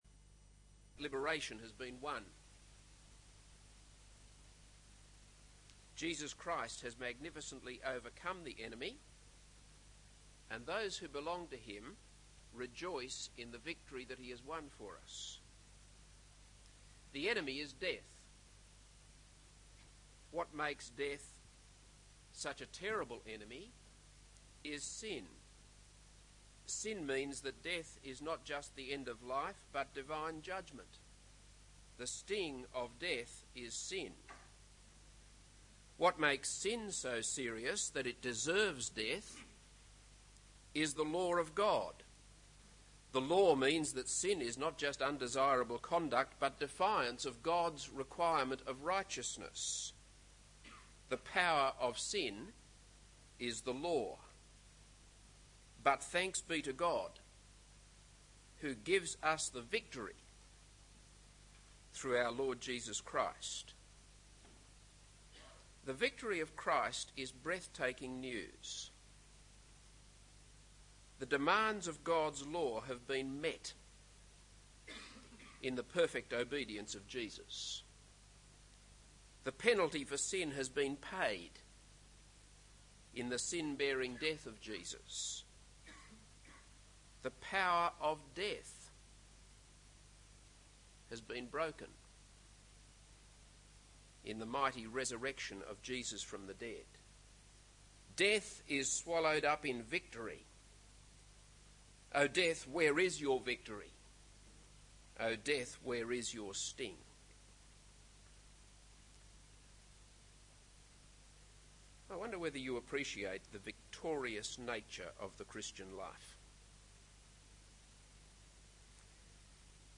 This is a sermon on 1 Samuel 17:41-58.